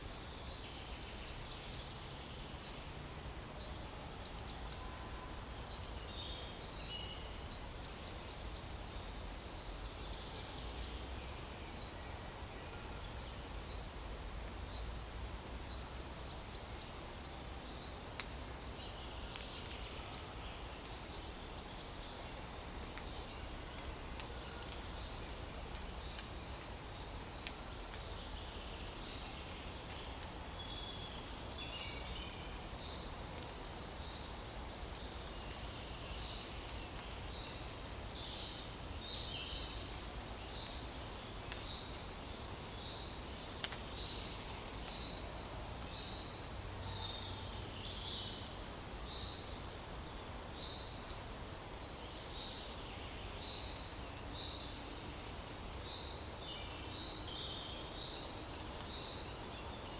Monitor PAM
Erithacus rubecula
Certhia familiaris
Regulus ignicapilla